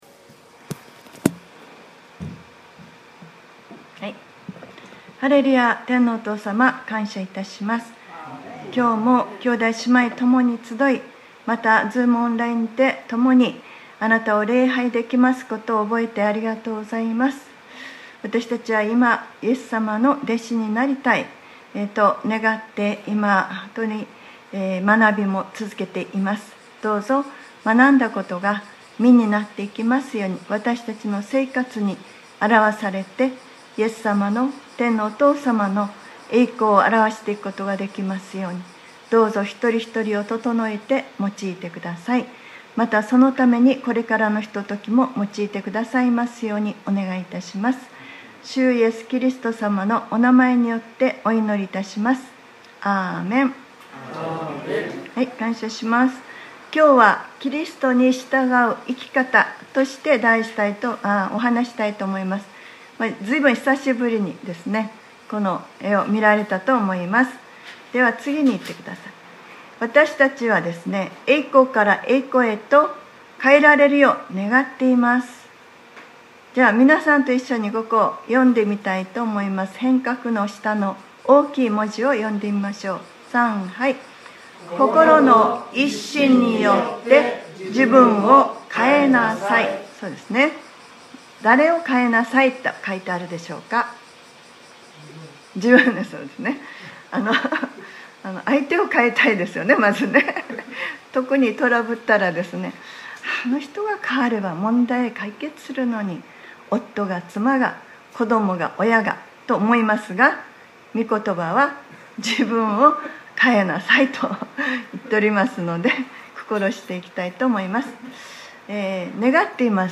2021年07月18日(日)礼拝説教『 本質を生きる 』